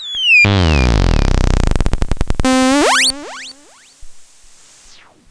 • Sound 17 square analog synth.wav
Sound_17_square_VSp_nE3.wav